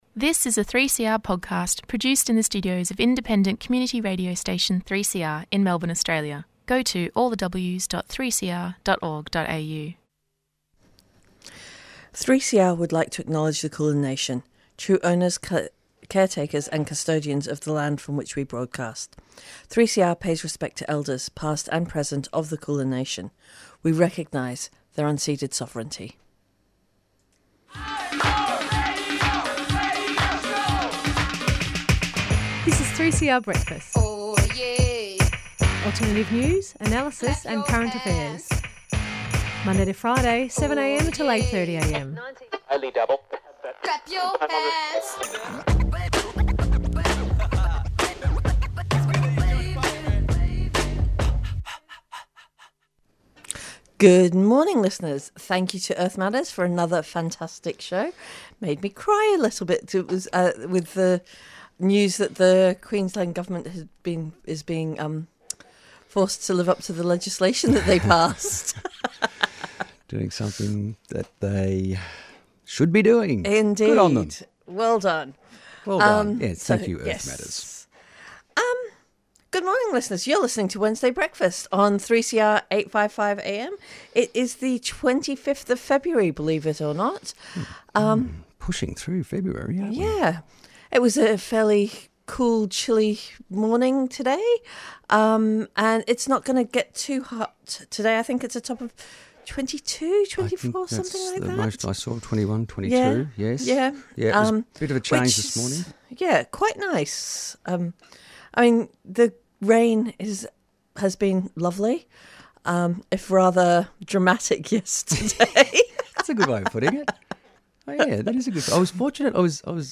Katie is the Aboriginal and Torres Strait Islander Social Justice Commissioner, with the AU human rights commission.